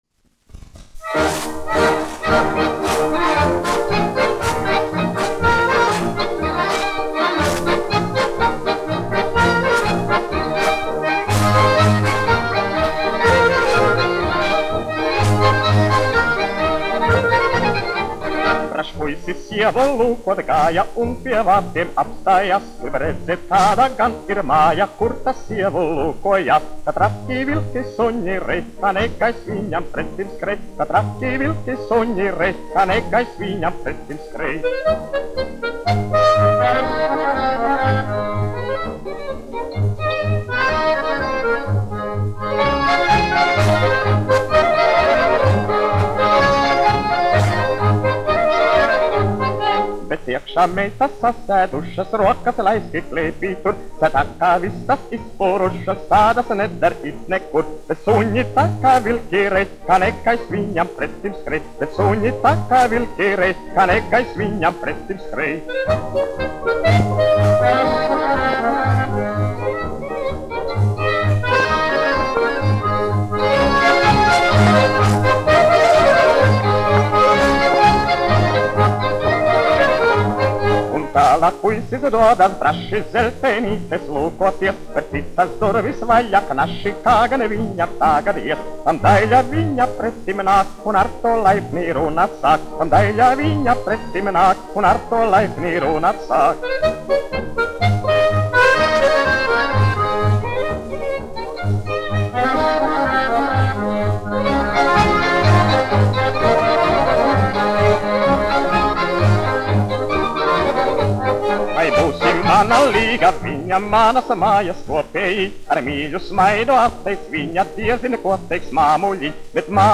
1 skpl. : analogs, 78 apgr/min, mono ; 25 cm
Krakovjaki
Tautas deju mūzika
Skaņuplate